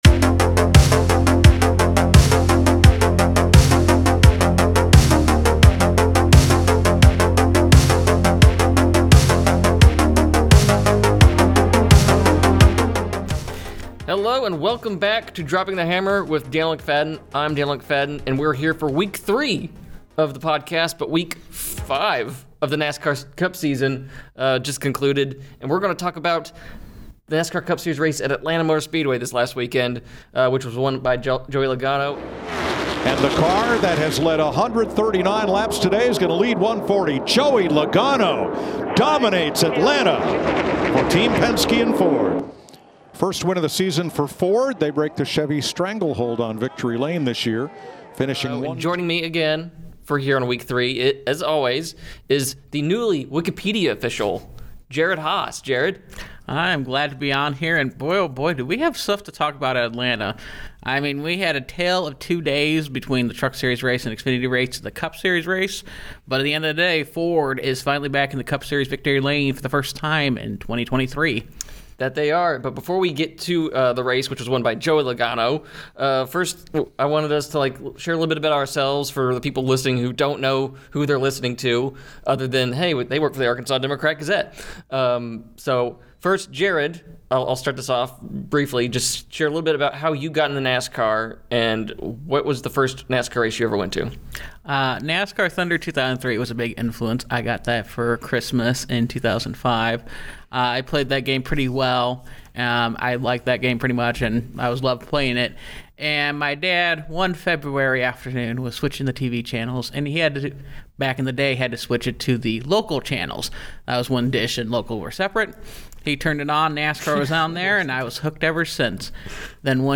Broadcast audio credit: NASCAR and Fox News conference audio credit: NASCAR Media, Speedway Motorsports